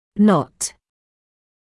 [nɔt][нот]не, нет, ни (образует отрицание)